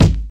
Mid Heavy Steel Kick Drum G# Key 09.wav
Royality free kick sound tuned to the G# note. Loudest frequency: 457Hz
.WAV .MP3 .OGG 0:00 / 0:01 Type Wav Duration 0:01 Size 52,24 KB Samplerate 44100 Hz Bitdepth 32 Channels Mono Royality free kick sound tuned to the G# note.
mid-heavy-steel-kick-drum-g-sharp-key-09-sCF.mp3